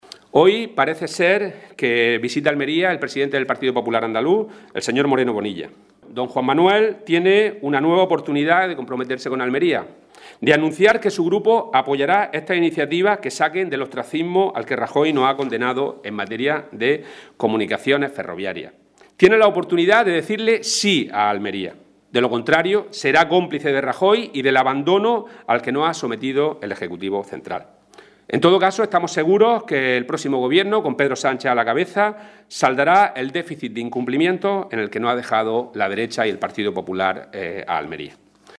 Rueda de prensa sobre iniciativas en materia de comunicaciones ferroviarias